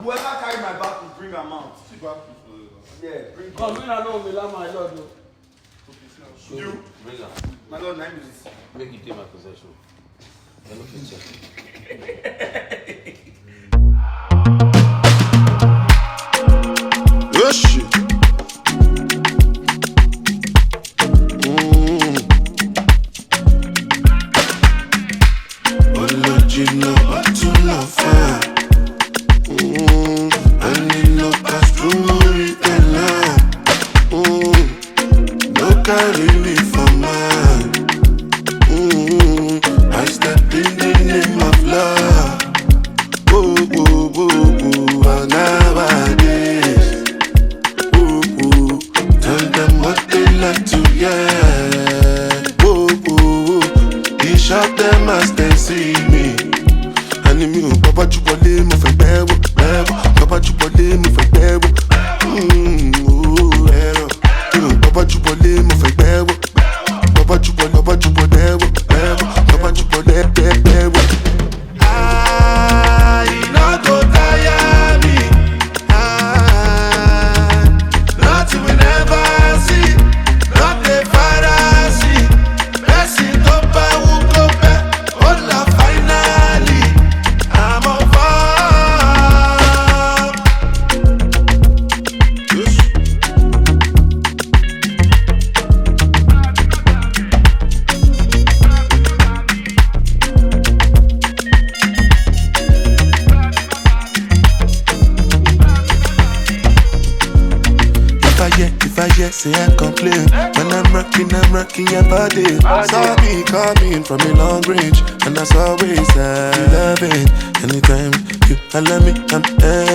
Afrobeats
gbedu